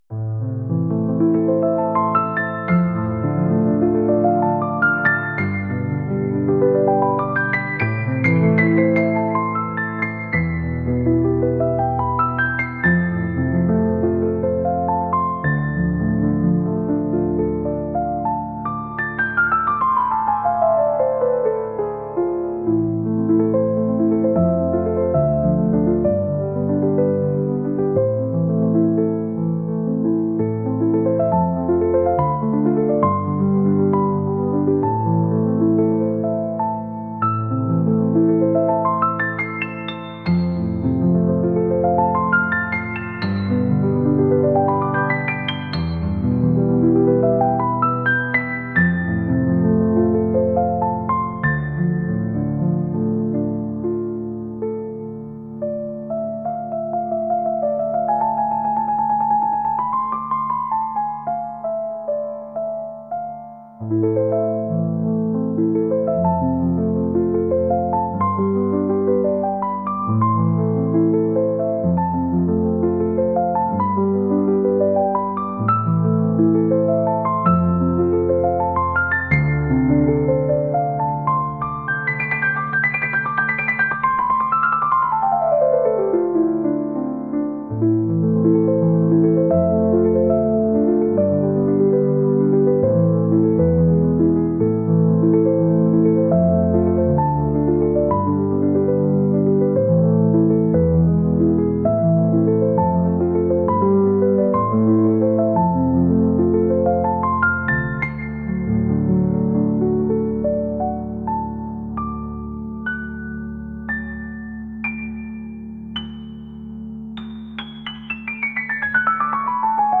重ための重厚なピアノ曲です。